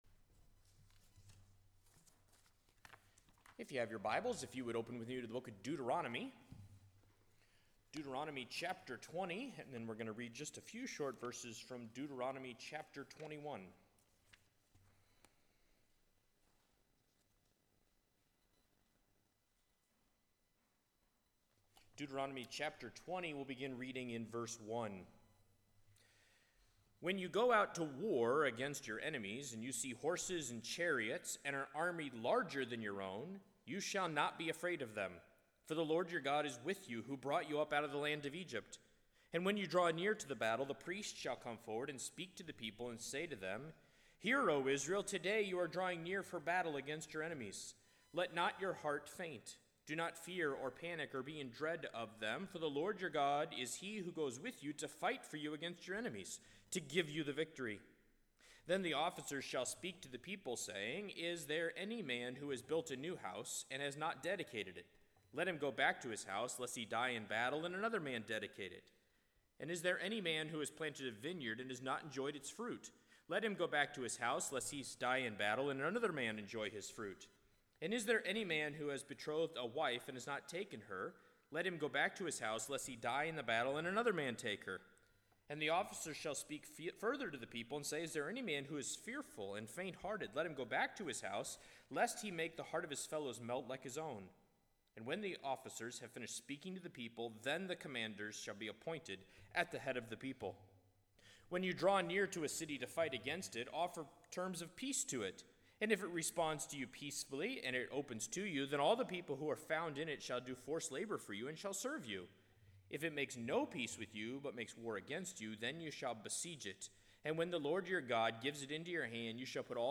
Deuteronomy 21:10-14 Service Type: Sunday Evening %todo_render% « 1 Samuel 16 Deuteronomy 20